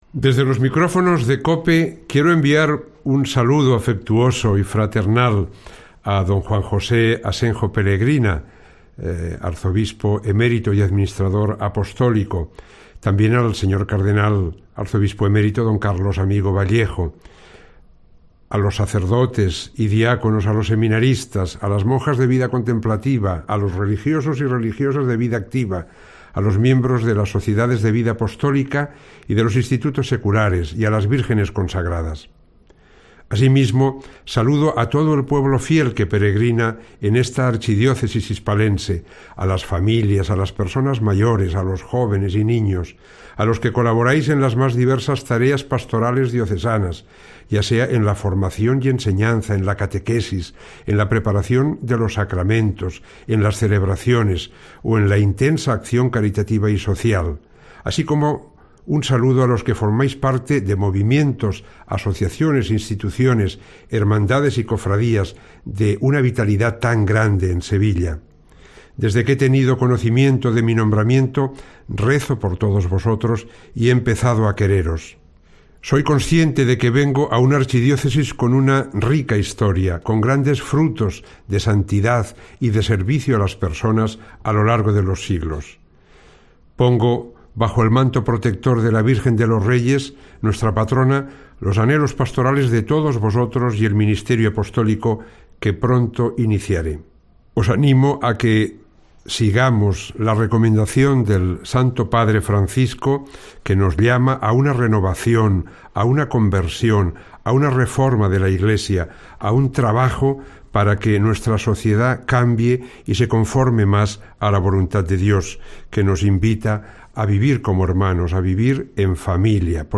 El recién nombrado por el Papa Francisco Arzobispo de Sevilla, Mons. José Ángel Saiz Meneses, ha querido dejar un mensaje grabado a los oyentes de COPE en Sevilla para mostrar su orgullo por esta nueva etapa que se abrirá el 12 de junio, fecha en la que tomará posesión como titular de la sede hispalense.